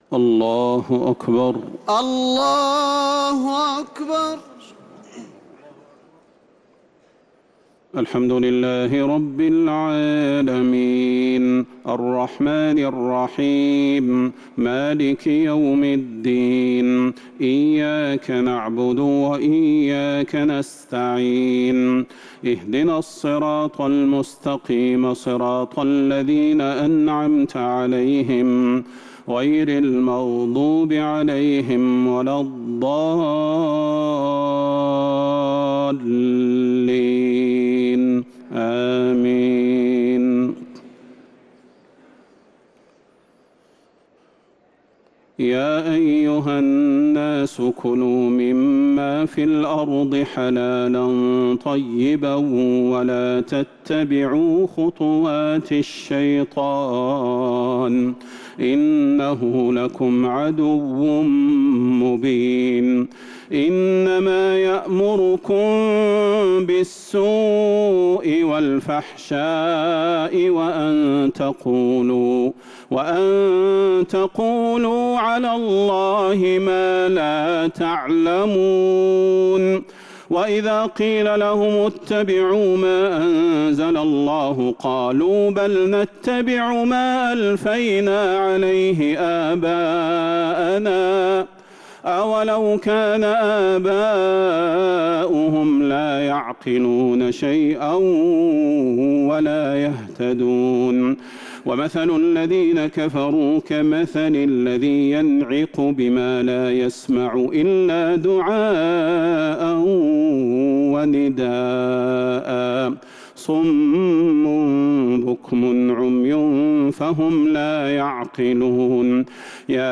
تراويح ليلة 2 رمضان 1447هـ من سورة البقرة ( 168-203 ) | Taraweeh 2nd night Ramadan 1447H > تراويح الحرم النبوي عام 1447 🕌 > التراويح - تلاوات الحرمين